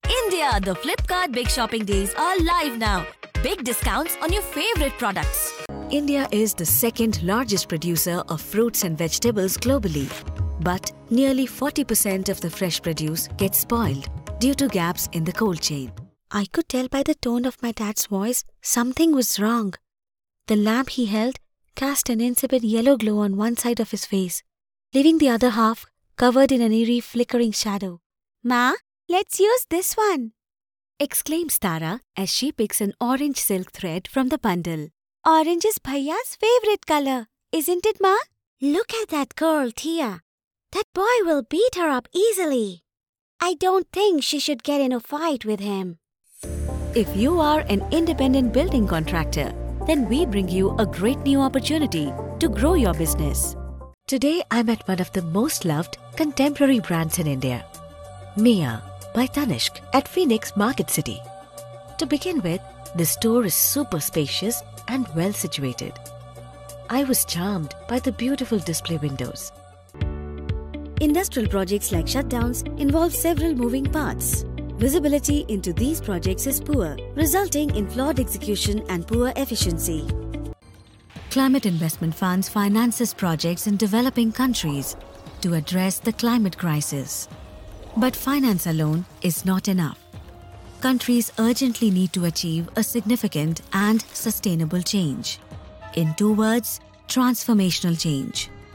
A Storyteller | Voice Artist | WARM, HONEST and BELIEVABLE voice for your brand | let's discuss your story | Broadcast quality professional studio setup | Equipped with SOURCE CONNECT| Recording for any studio globally
English Voice Range
Indian, Neutral, Global